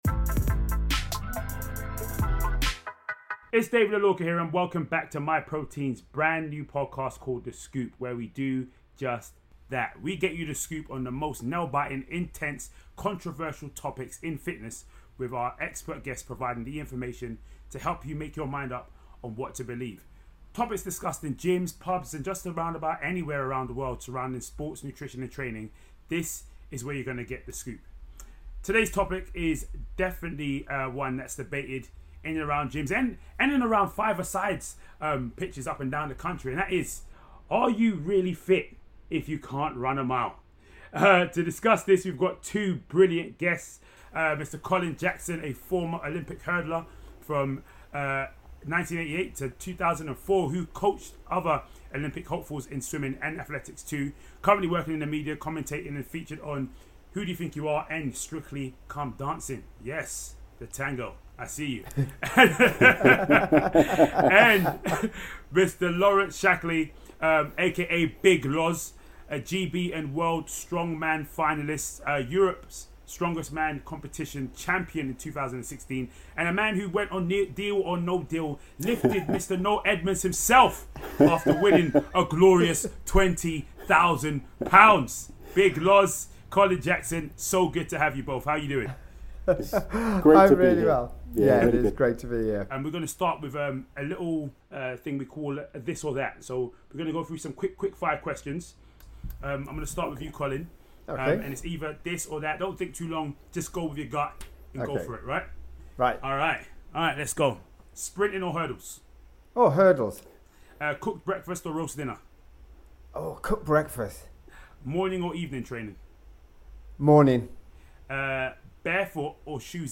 On this week’s episode of Myprotein’s The Scoop, we’ll be asking exactly that to Olympian hurdler and sprinter, Colin Jackson, and Strongman Champ, Laurence Shahlaei.